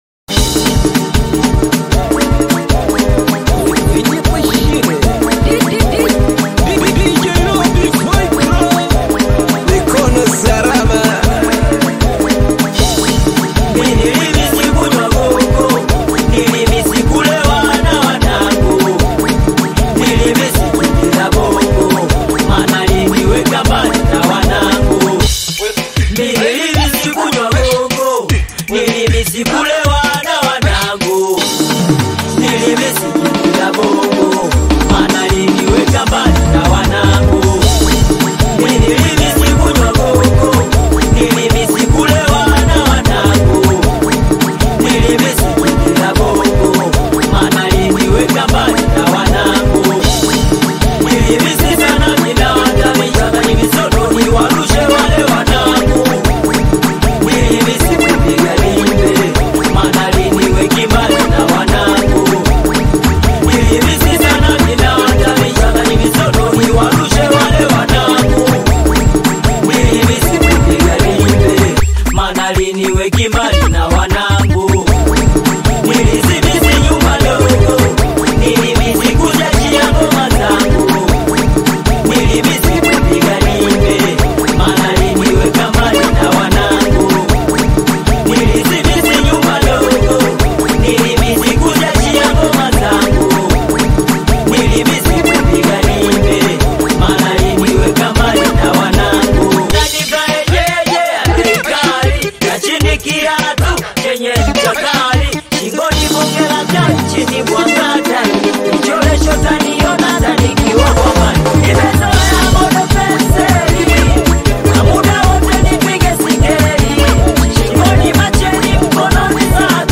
Tanzanian Singeli single